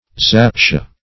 Zaptiah \Zap"ti*ah\, n. A Turkish policeman.